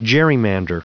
Prononciation du mot gerrymander en anglais (fichier audio)
Prononciation du mot : gerrymander